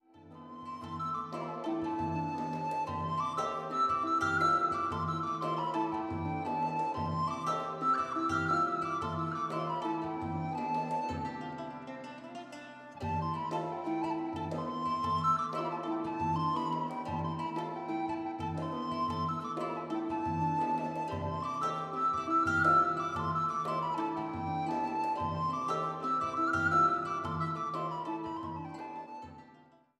eine bundlose Knickhalslaute
INSTRUMENTAL